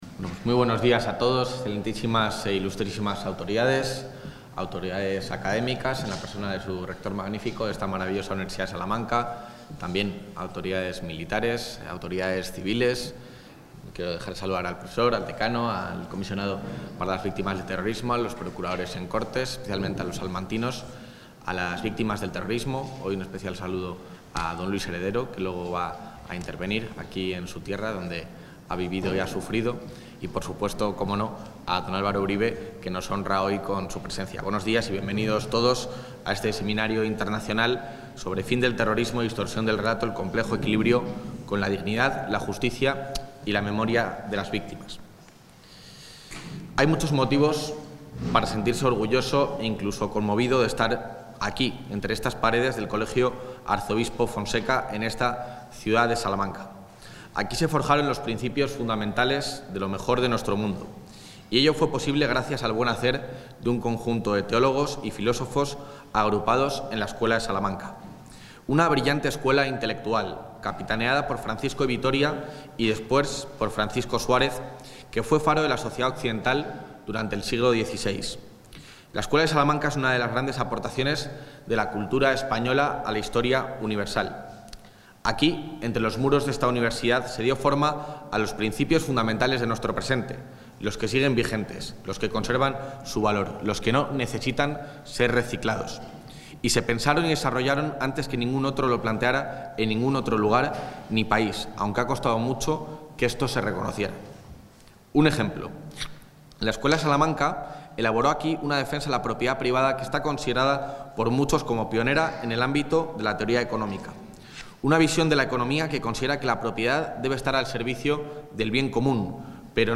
Intervención del vicepresidente de la Junta.
El vicepresidente del Gobierno autonómico alerta sobre la existencia de una “guerra de relatos” sobre el terrorismo en un seminario internacional celebrado en el Colegio Fonseca